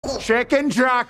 boss_speech.mp3